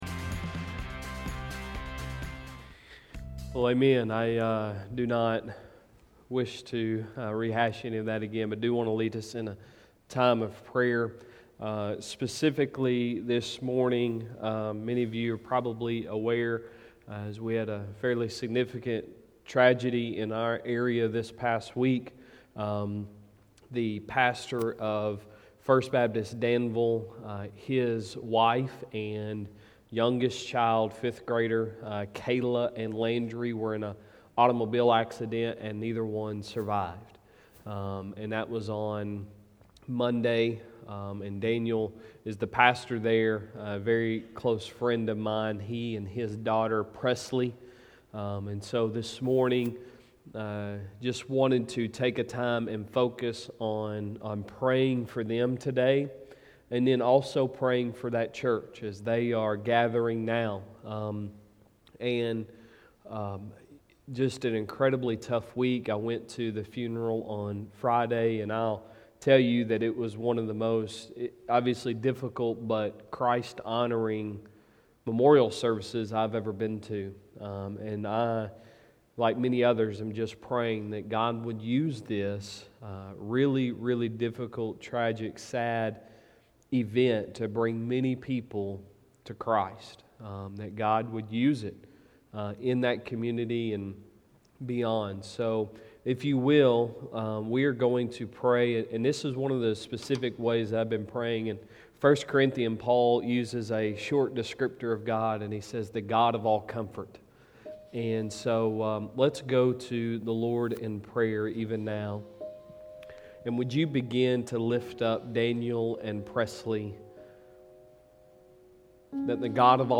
Sunday Sermon March 7, 2021